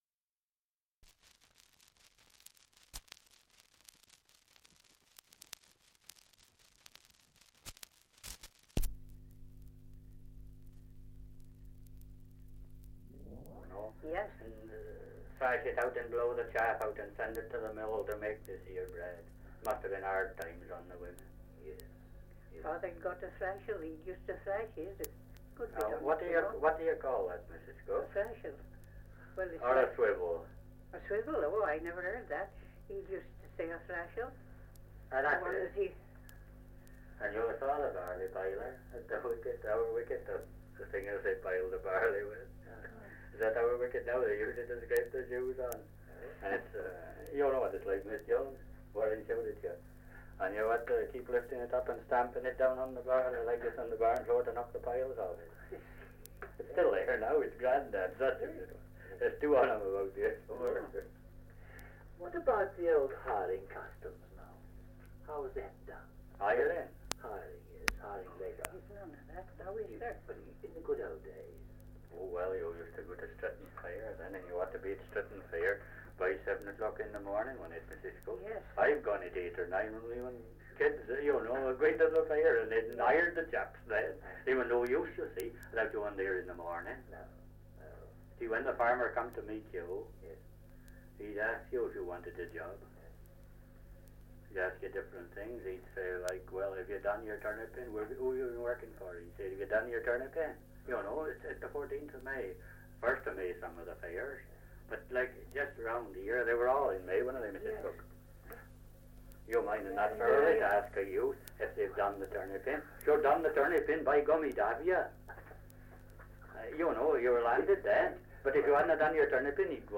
Dialect recording in Cardington, Shropshire
78 r.p.m., cellulose nitrate on aluminium